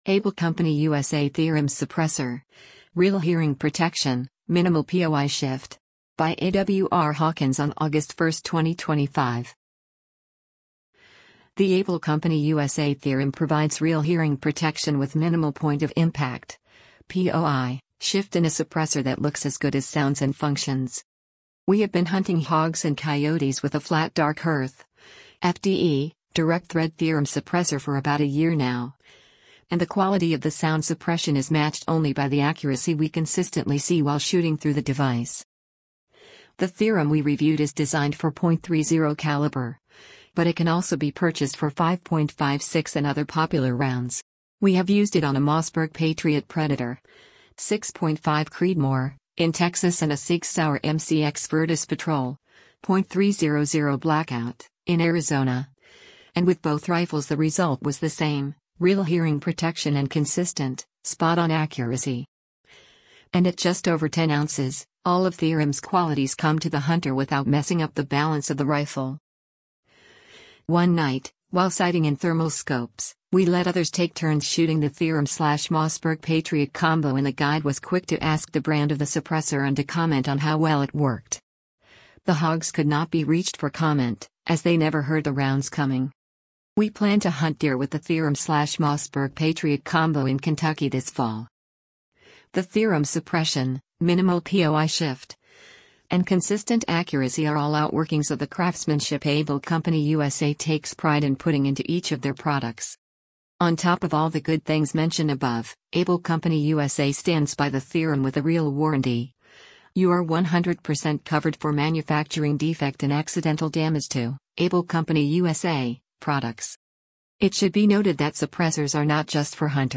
gun suppressor